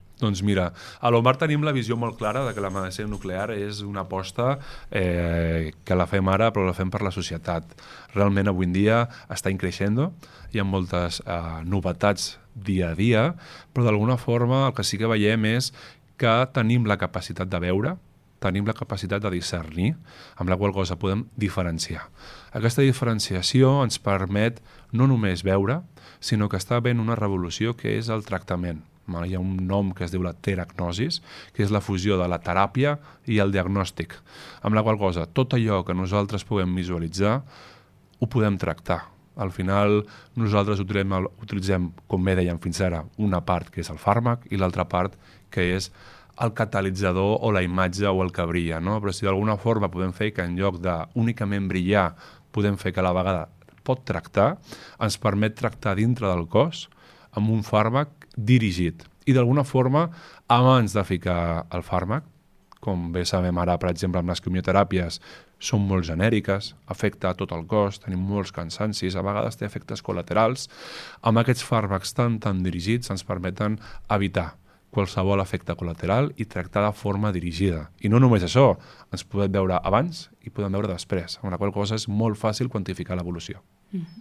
En una entrevista a Ràdio Capital